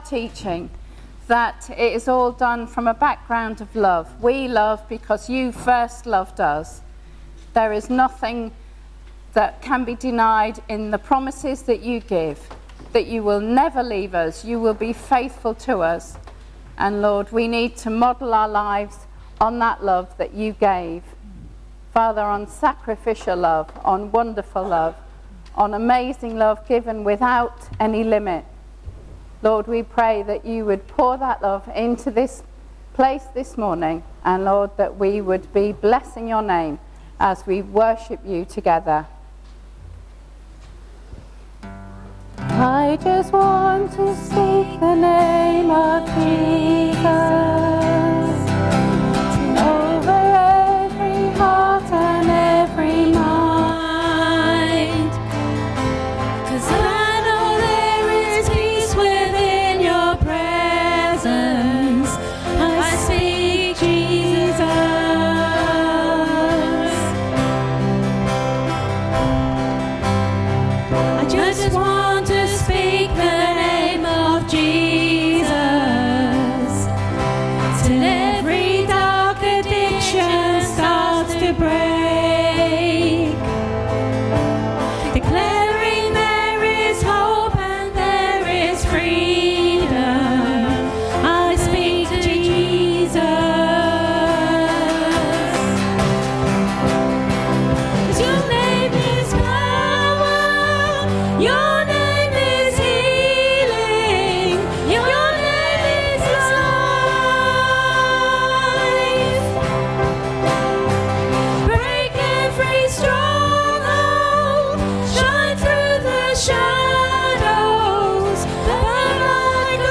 The service this morning considers aspects of relationships and intimacy. This service also includes communion.
Service Audio